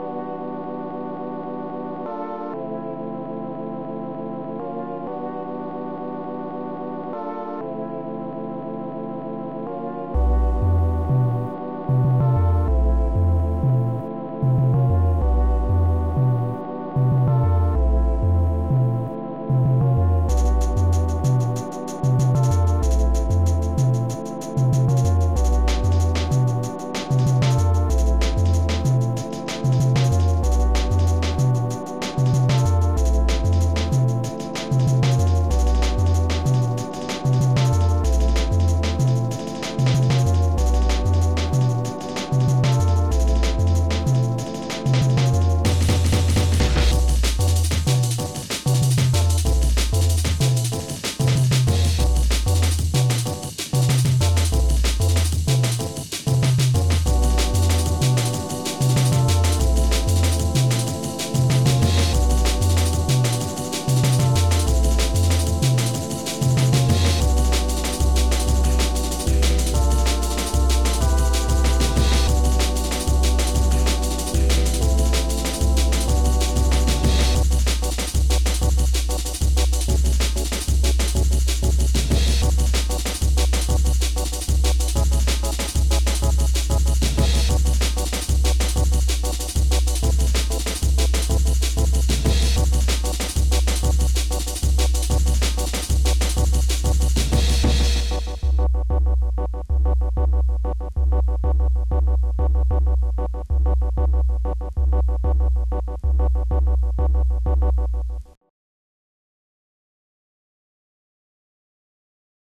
unnamed dnb tune (.it, 9ch)
fadeout effects used at the end of the song.